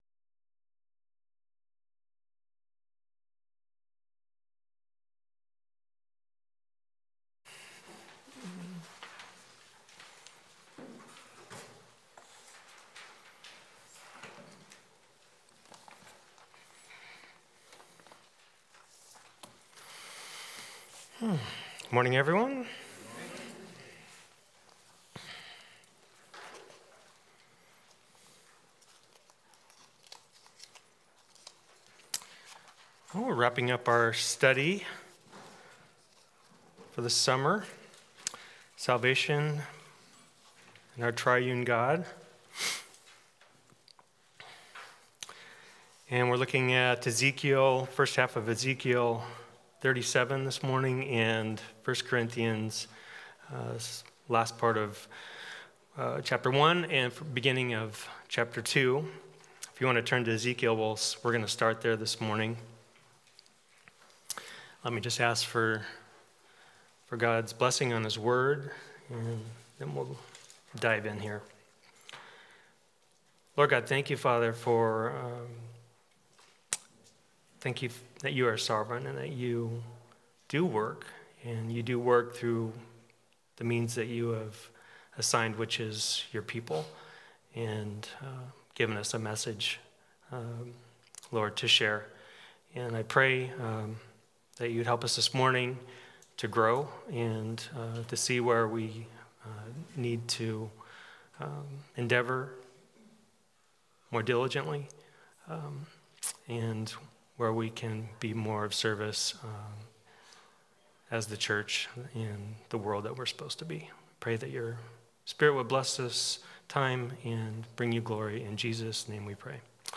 SALVATION The Sovereign Grace of our Triune God Passage: Ezekiel 37:1-14; 1 Cor 1:18-2:5 Service Type: Sunday School « The Way of Salvation